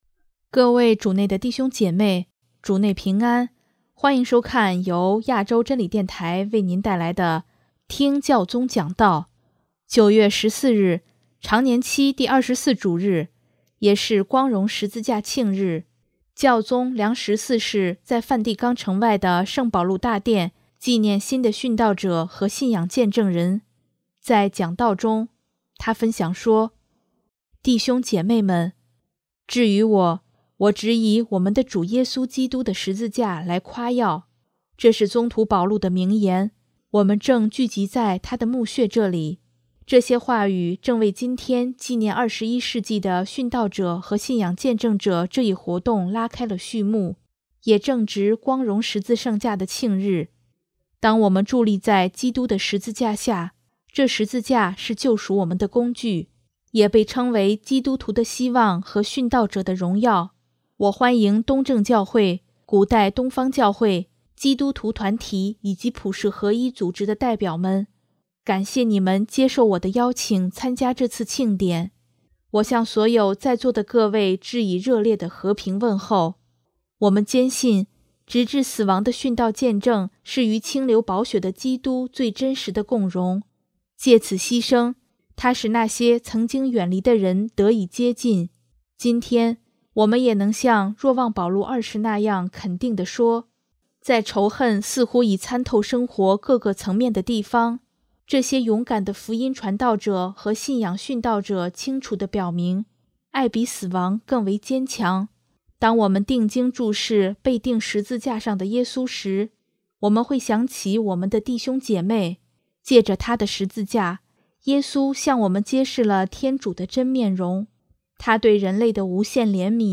9月14日，常年期第二十四主日，也是光荣十字圣架庆日，教宗良十四世在梵蒂冈城外的圣保禄大殿，纪念新的殉道者和信仰见证人，在讲道中，他分享说：